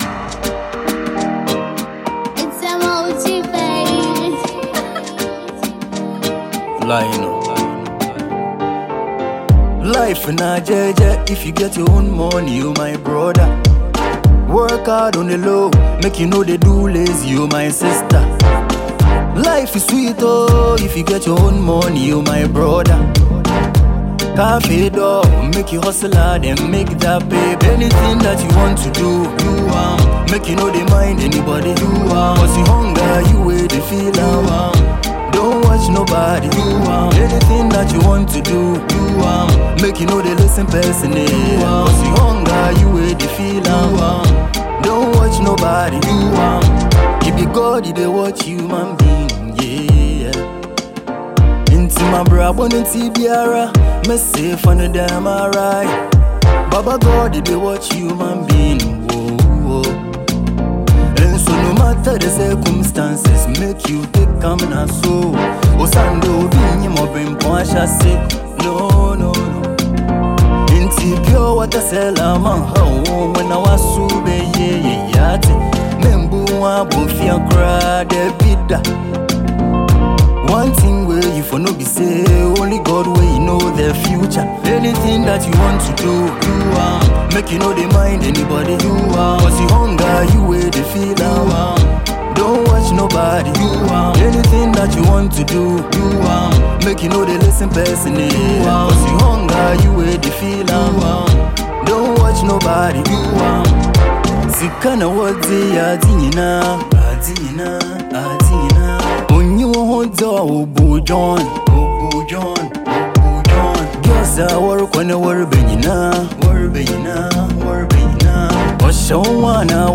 Afropop/Highlife